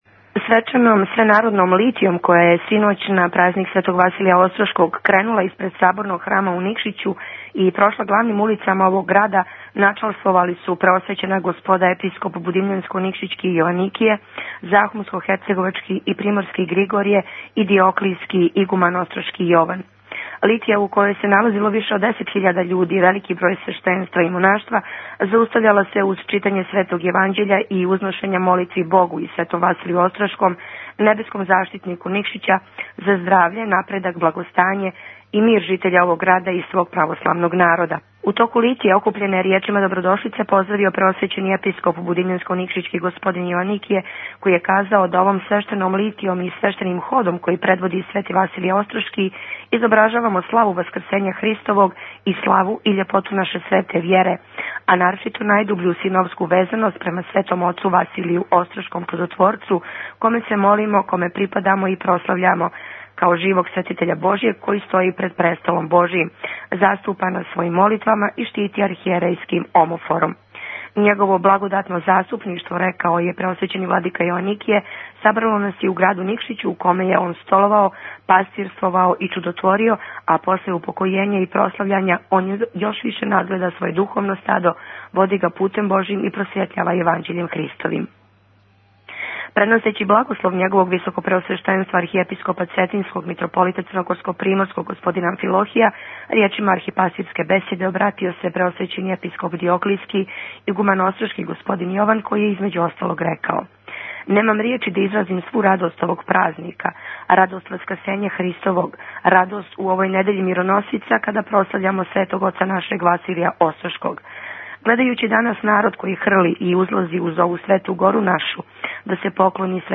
У славу Светог Василија Острошког Чудотворца Tagged: Извјештаји